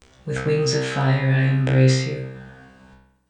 Processing: Granulated, 12 voices, 1:4 - 1:2, then 10:1